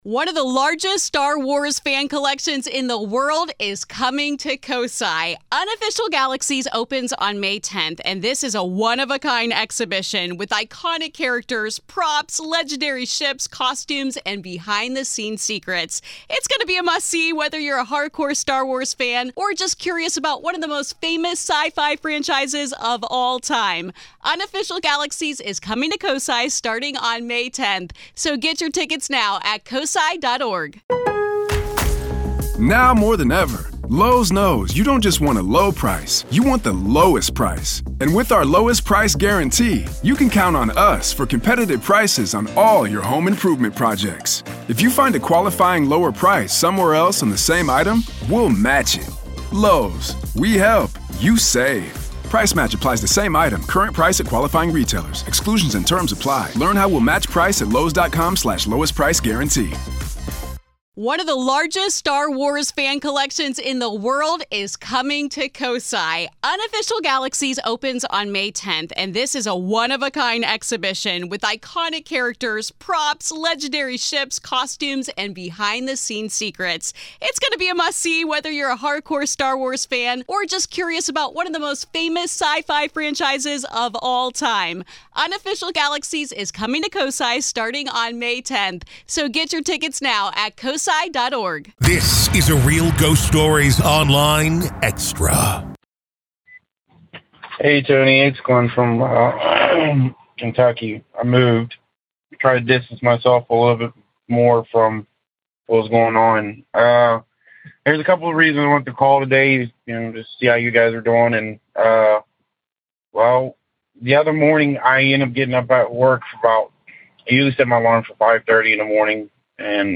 When nightmares strike both of your children at the same time, you know something isn’t right. In this chilling episode, a caller recounts a disturbing morning that led to urgent prayers, sage burning, and questions about the lingering energy from his former workplace—a place ...